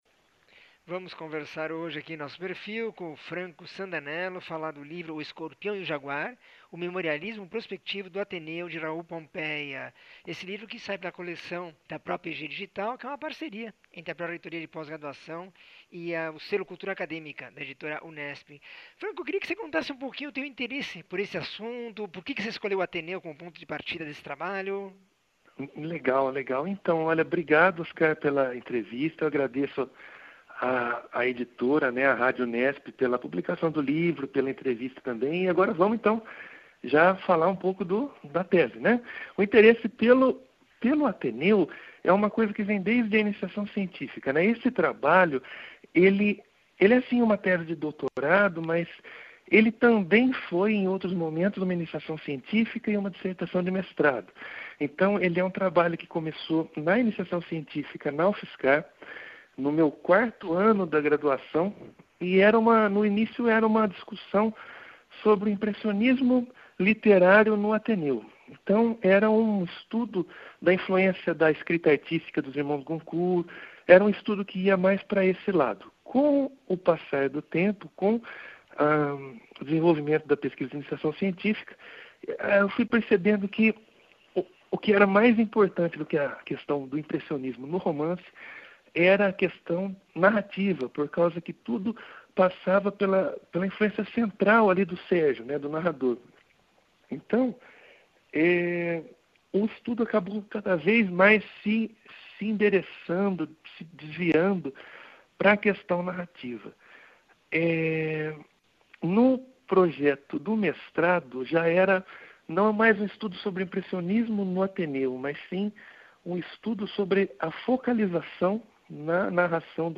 entrevista 2502